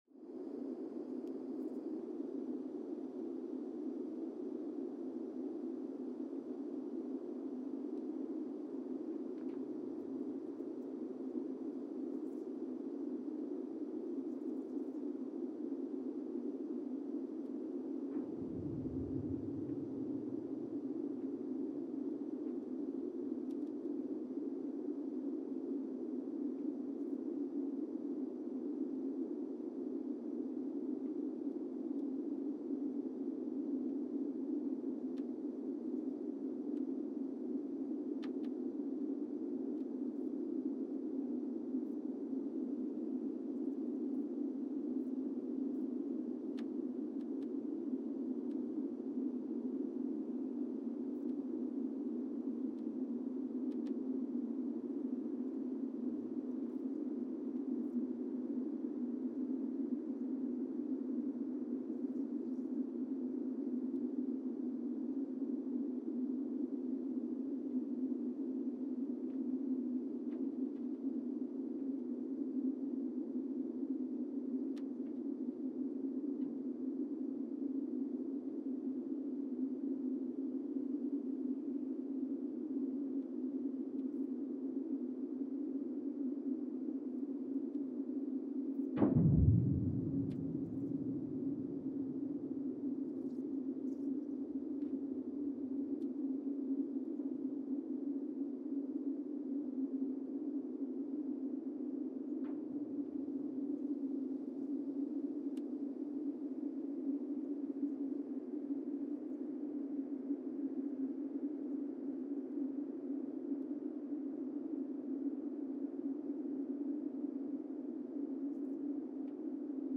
Sensor : Teledyne Geotech KS-54000 borehole 3 component system
Recorder : Quanterra Q330HR @ 20 Hz
Speedup : ×1,800 (transposed up about 11 octaves)
Loop duration (audio) : 05:36 (stereo)
SoX post-processing : highpass -2 90 highpass -2 90